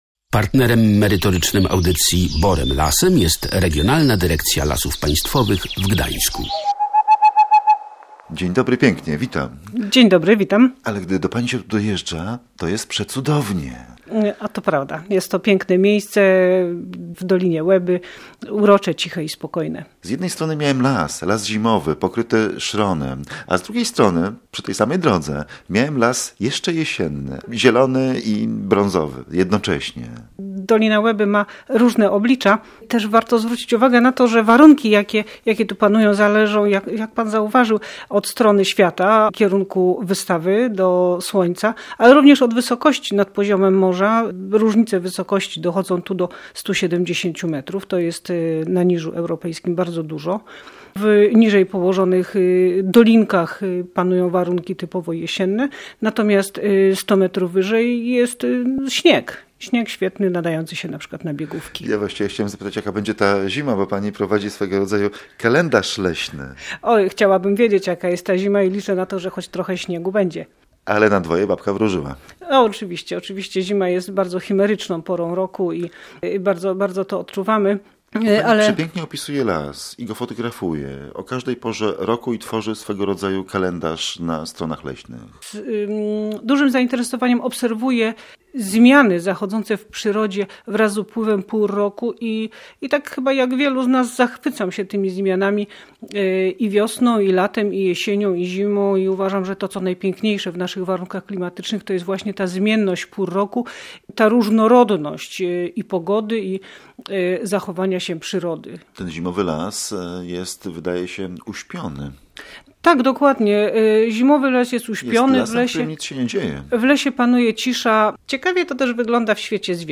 w rozmowie o grudniowym lesie